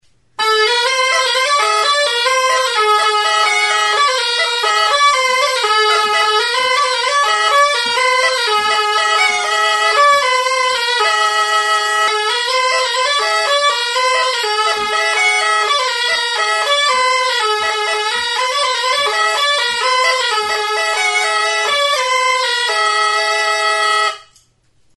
Aérophones -> Anches -> Simple battante (clarinette)
Enregistré avec cet instrument de musique.
ALBOKA
Klarinete bikoitza da.